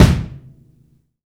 INSKICK14 -R.wav